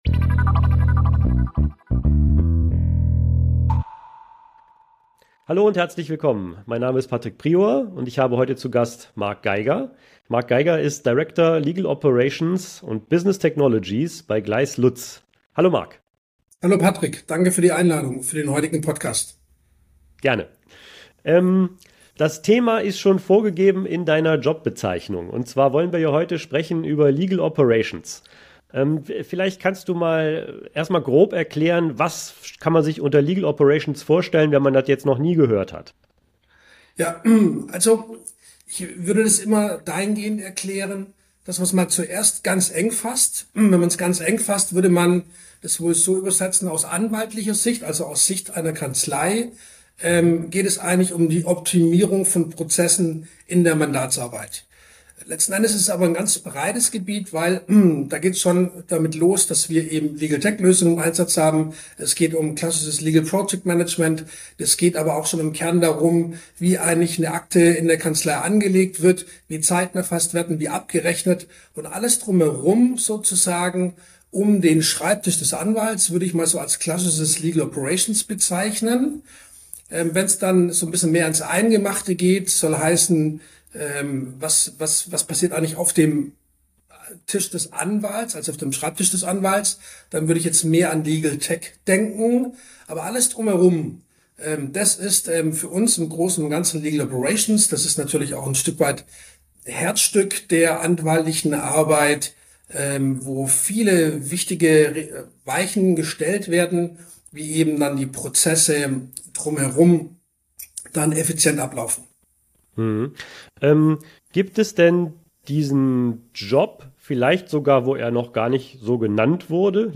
Legal Operations bei Gleiss Lutz ~ Legal Tech Verzeichnis - Legal Tech & Legal KI Talk Podcast